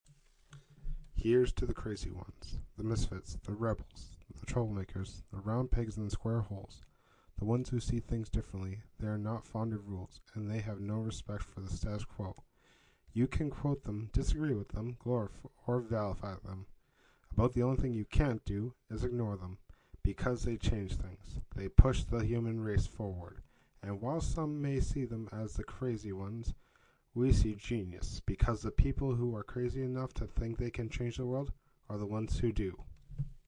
学校铃声
描述：学校铃声。 用Rode NTG2麦克风录音。
标签： 警报 学校 铃声 上课 下课
声道单声道